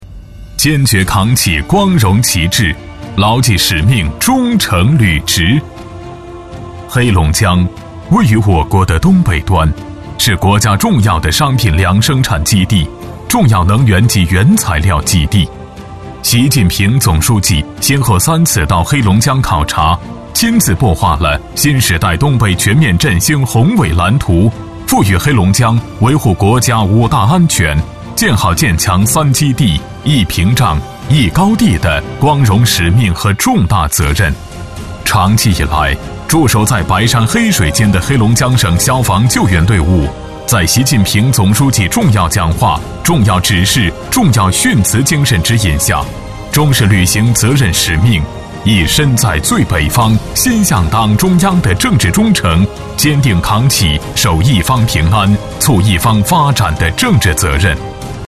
样音试听 - 红樱桃配音-真咖配音-500+真人配音老师 | 宣传片汇报纪录动画英文粤语配音首选平台